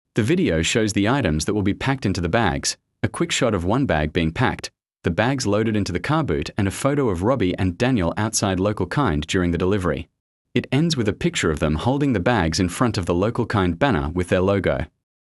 Audio Description
Homelessness-Week-2025-video-explainer.mp3